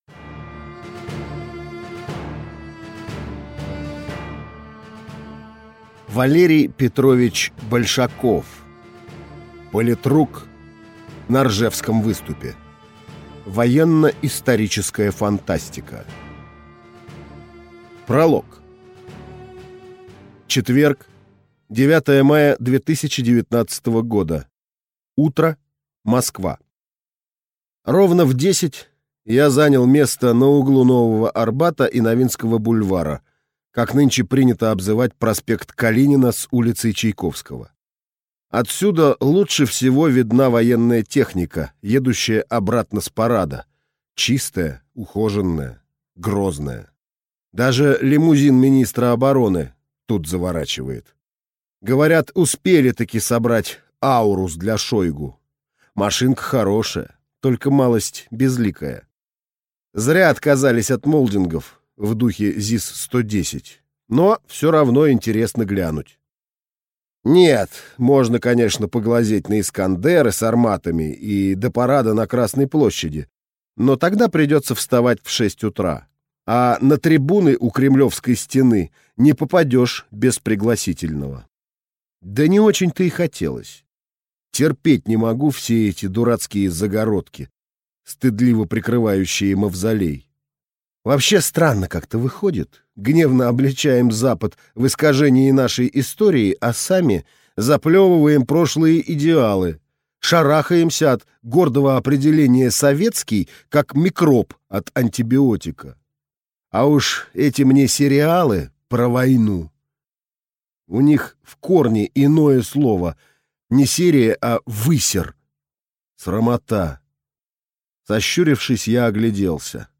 Аудиокнига Политрук. На Ржевском выступе | Библиотека аудиокниг